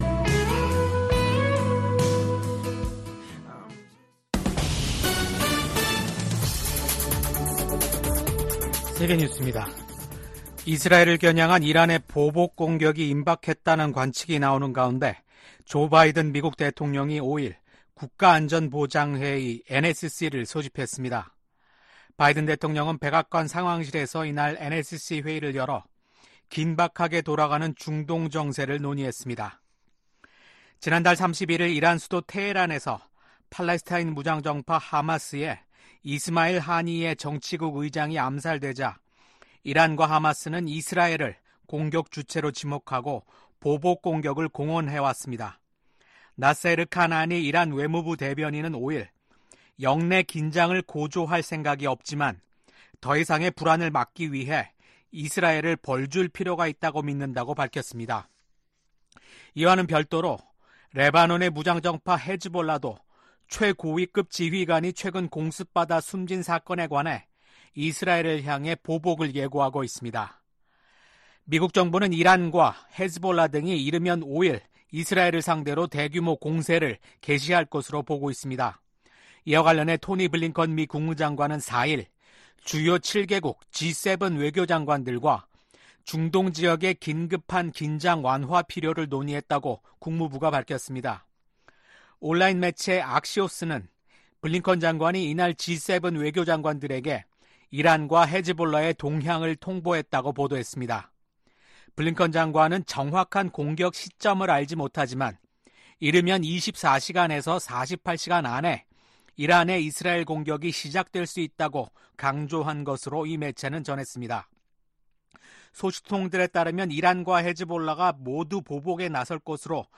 VOA 한국어 아침 뉴스 프로그램 '워싱턴 뉴스 광장' 2024년 8월 6일 방송입니다. 북한이 핵탄두 장착이 가능한 신형 전술탄도미사일 발사대를 대규모 전방 배치한다고 발표했습니다.